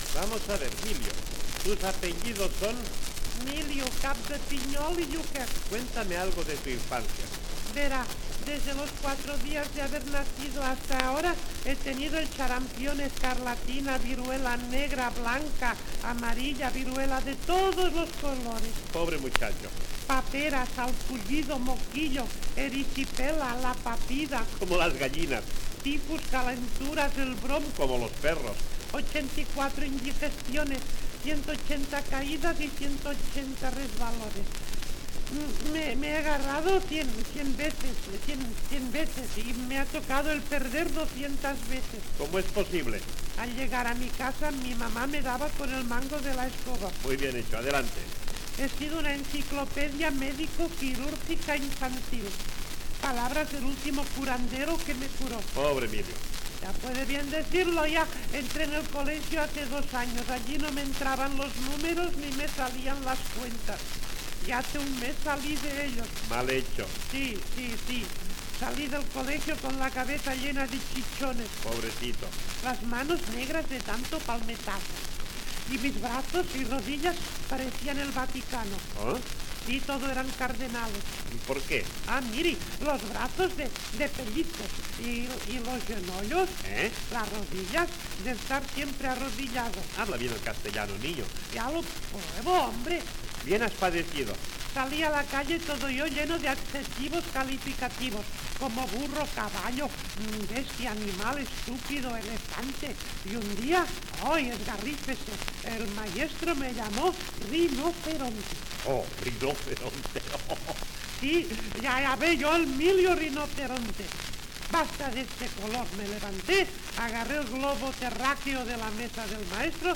Toresky pregunta a Miliu sobre la seva infantesa: la seva salut i els seus estudis.
Entreteniment